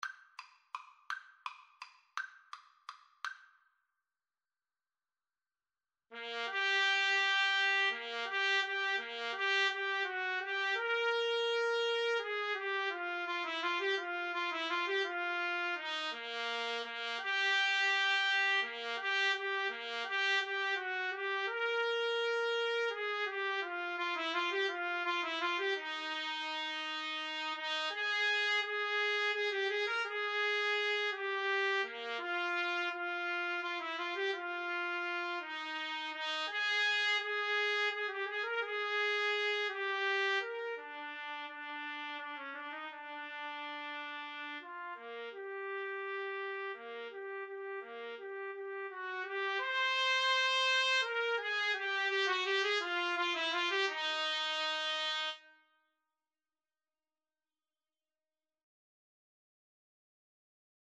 3/4 (View more 3/4 Music)
Tempo di valse =168
Classical (View more Classical Trumpet-Trombone Duet Music)